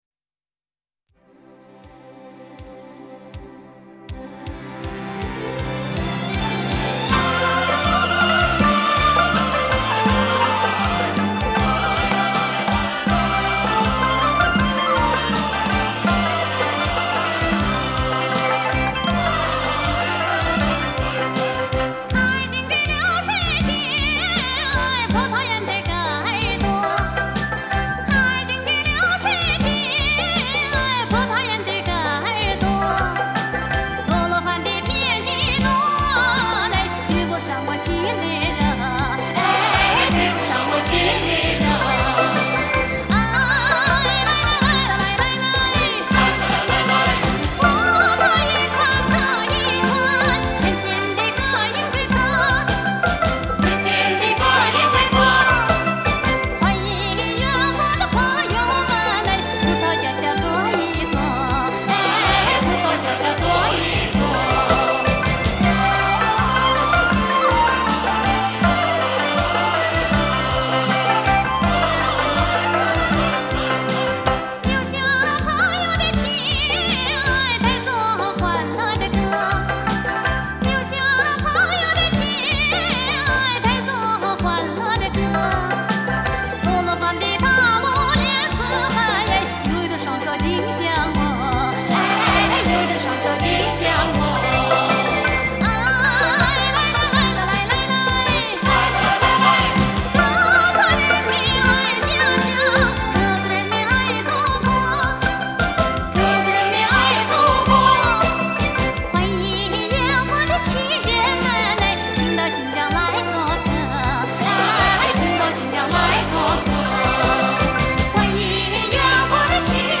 专辑风格：中国民歌